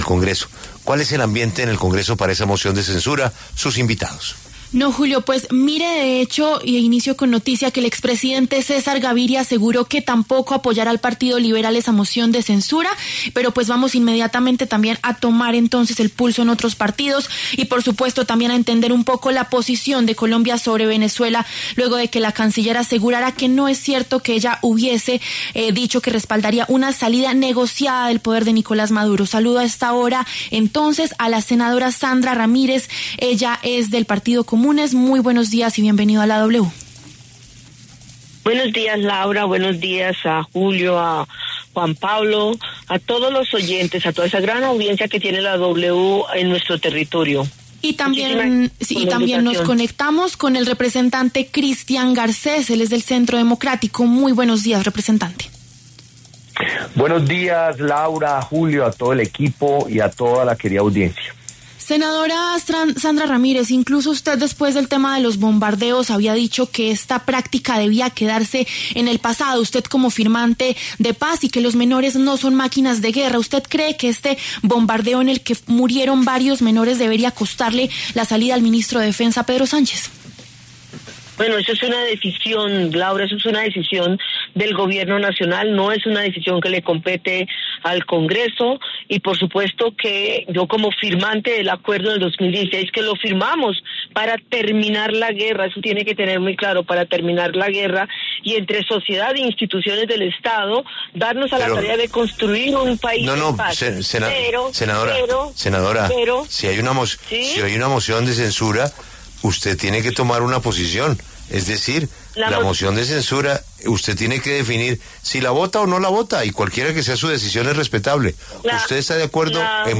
Los congresistas Christian Garcés, del Centro Democrático, y Sandra Ramírez, de Comunes, pasaron por los micrófonos de La W. También hablaron sobre una posible salida negociada de Nicolás Maduro del poder en Venezuela, con apoyo de Colombia.